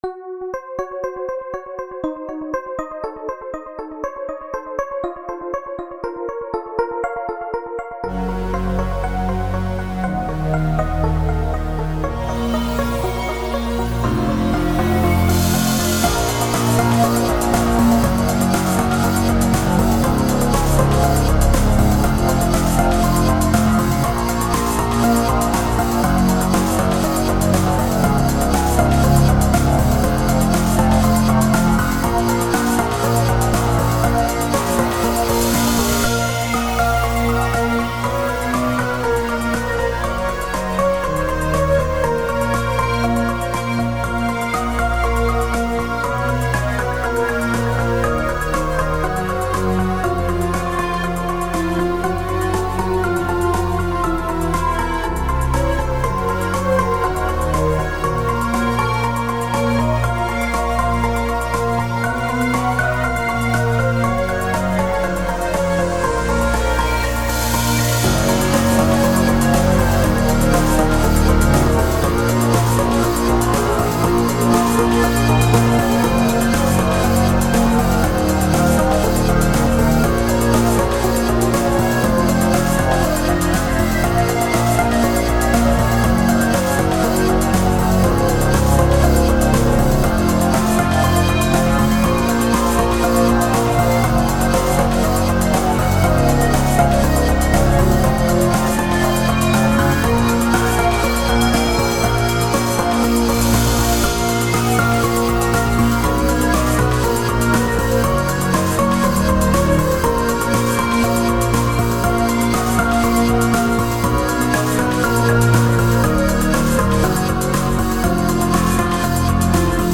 Mp3-версия, инструментал (5,7 мб)